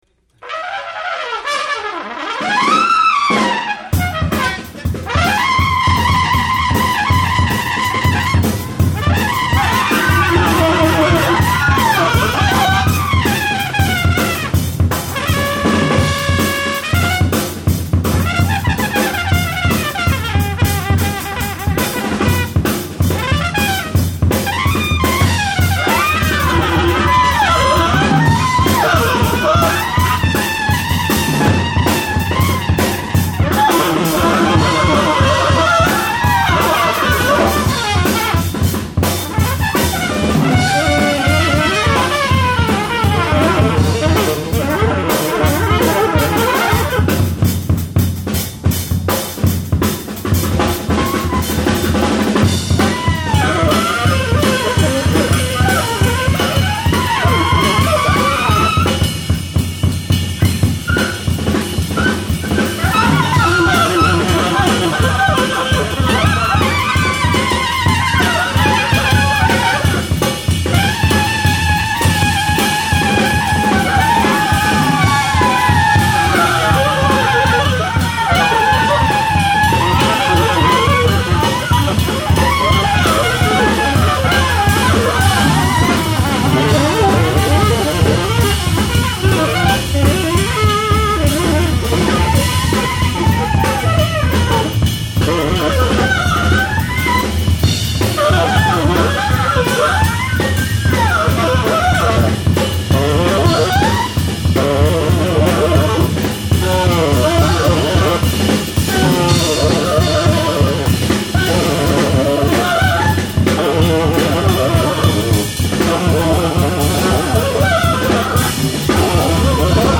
Recorded in concert at the Vision Festival, The Center,